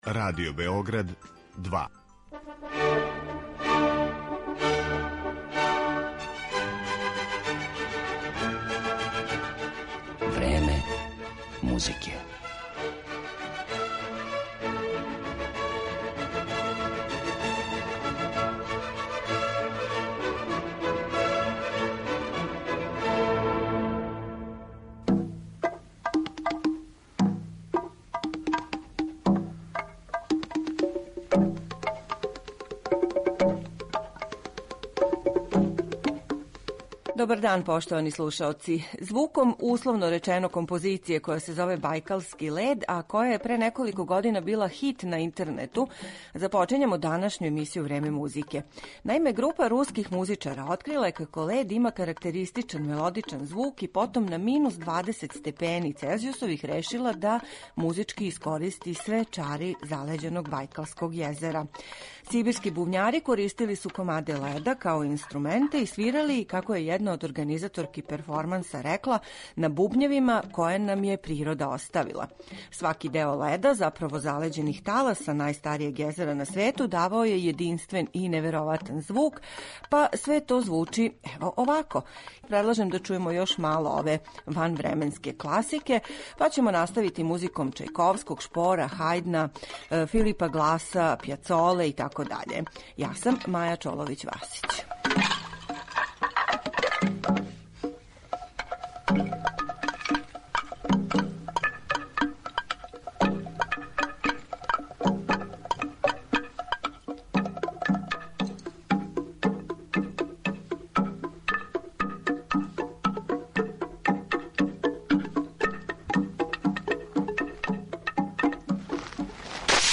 У данашњој емисији слушаћете избор ових композиција у извођењу врхунских солиста и ансамбала.